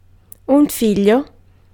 Ääntäminen
France: IPA: [œ̃.n‿ɑ̃.fɑ̃] Tuntematon aksentti: IPA: /ɑ̃.fɑ̃/